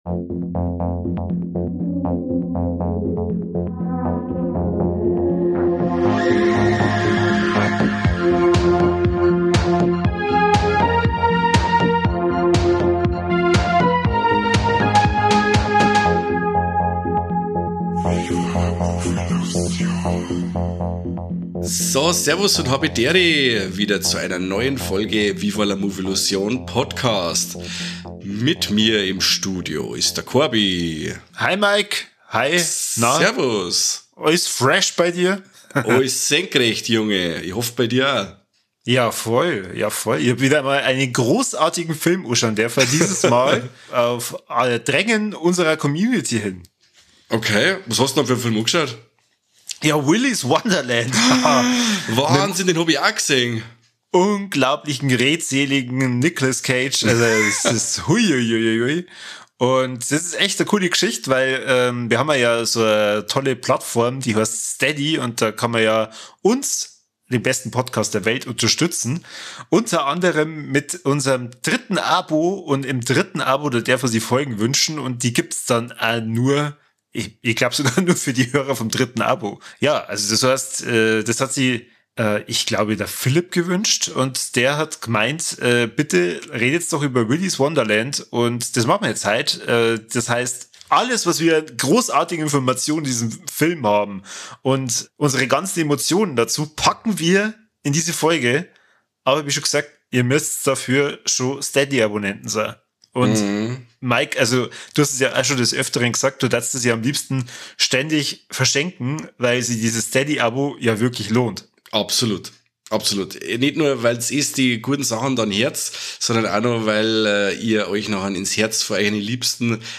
„Viva la Movielución" ist der bayerische Film-Podcast aus Niederbayern – ehrlich, ungefiltert und auf Dialekt.